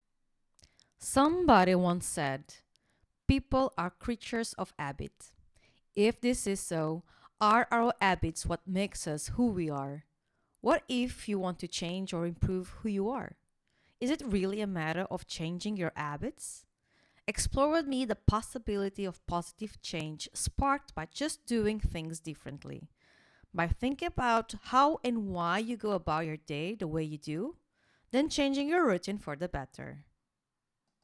English - USA and Canada
Young Adult